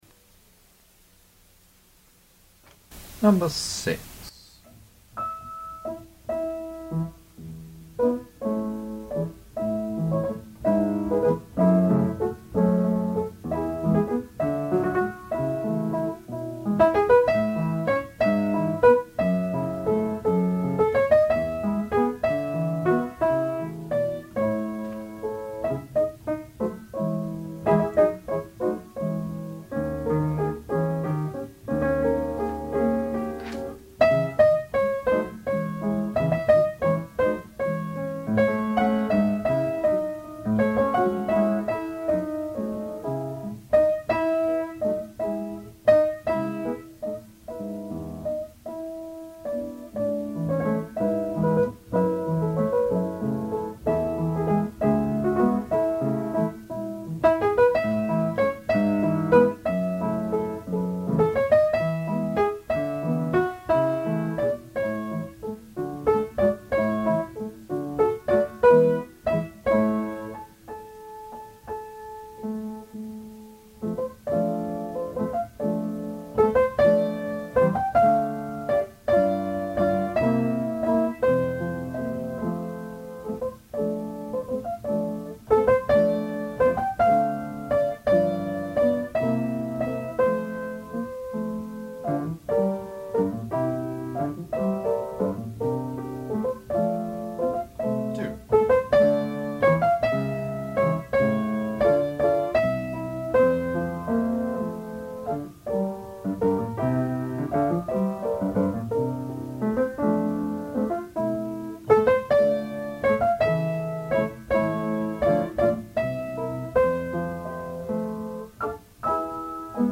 Rehearsal Tracks (mp3 files)
Soprano Section